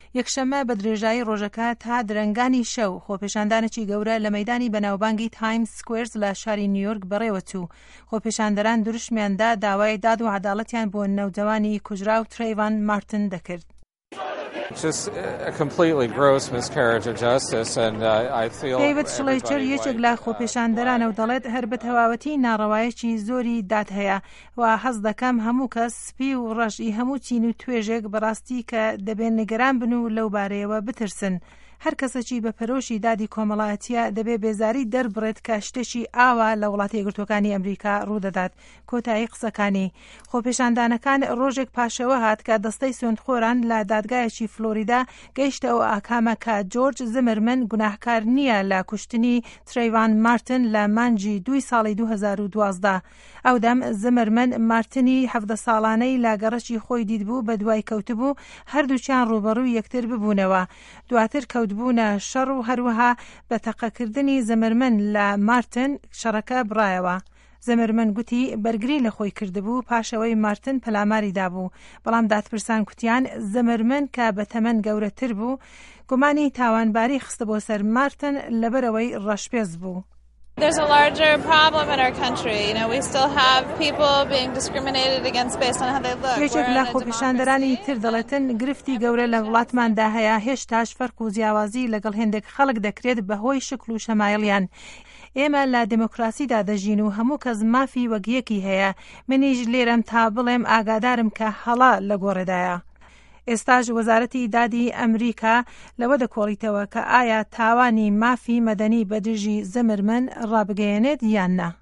ده‌قی ڕاپـۆرته‌که‌